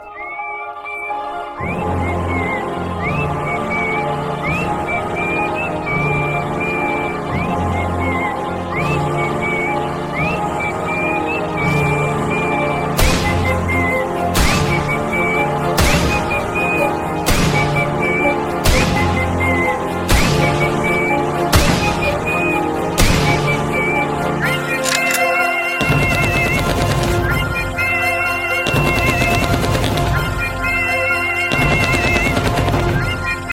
Categories BGM Ringtones